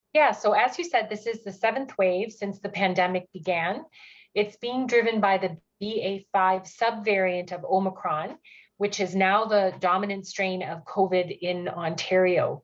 Medical Officer of Health for Huron Perth Dr. Miriam Klassen  says this current wave of COVID is the most dominant wave in Ontario